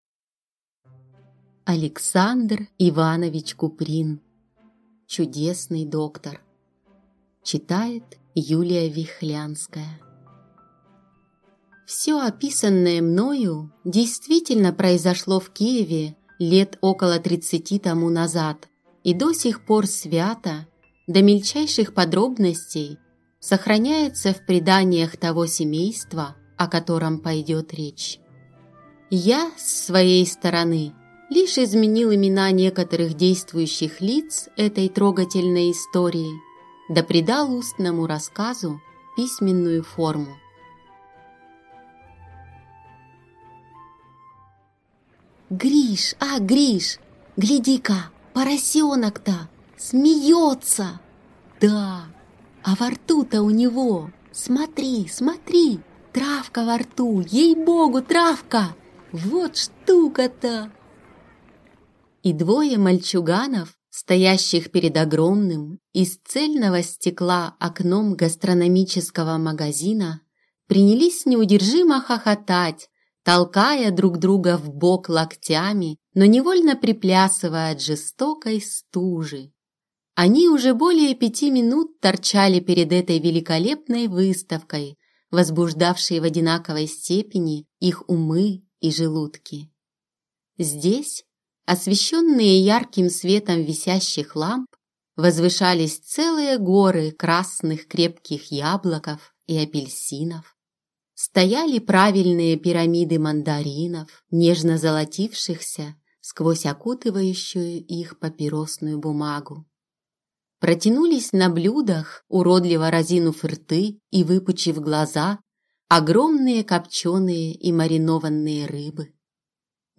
Аудиокнига Чудесный доктор | Библиотека аудиокниг
Прослушать и бесплатно скачать фрагмент аудиокниги